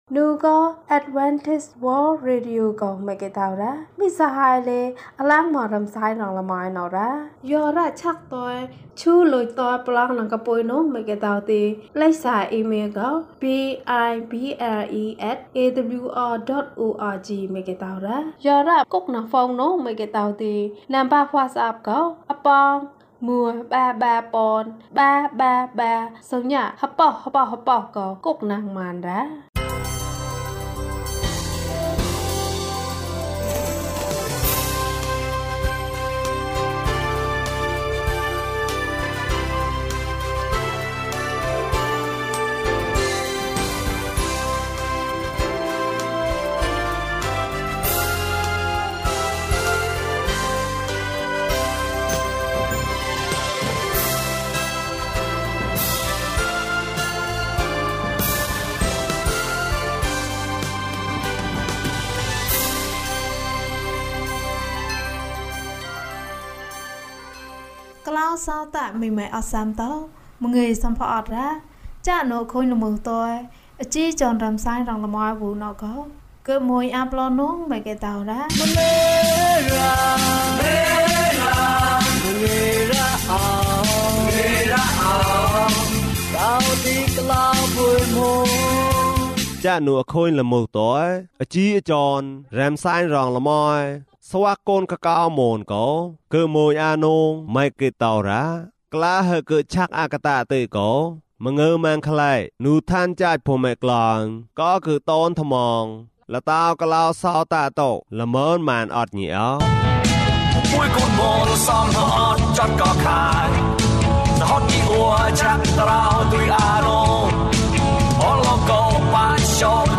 ဘုရားသခင်နှင့်အတူ ကြီးပွားပါ။၀၁ ကျန်းမာခြင်းအကြောင်းအရာ။ ဓမ္မသီချင်း။ တရားဒေသနာ။